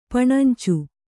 ♪ paṇancu